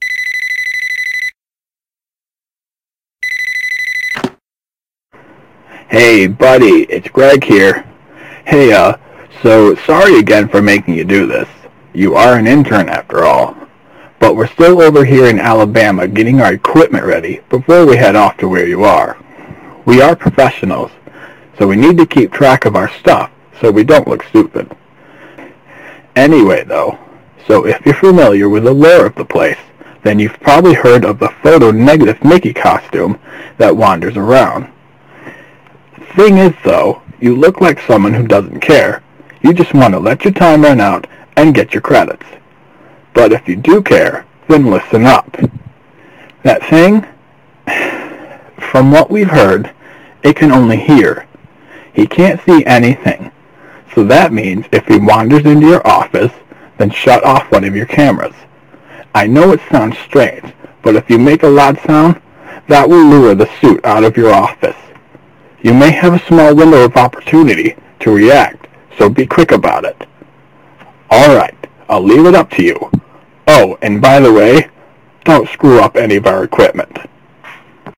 SFX